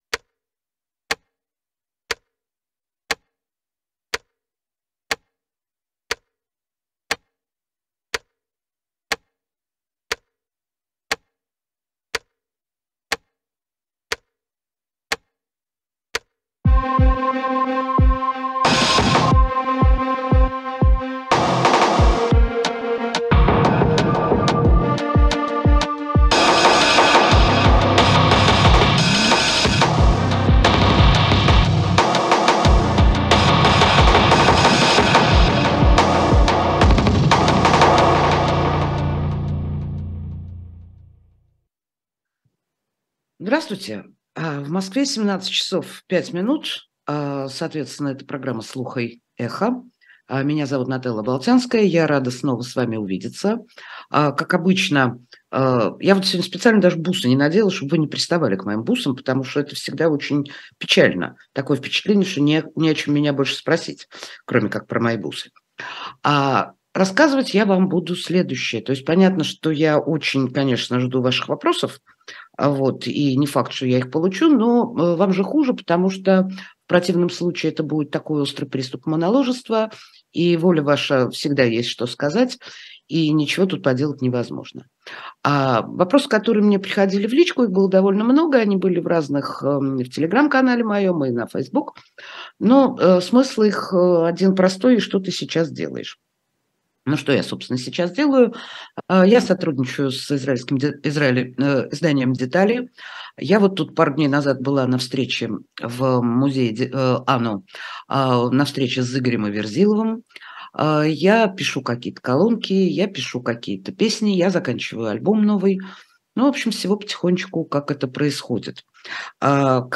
Нателла Болтянская отвечает на вопросы зрителей в прямом эфире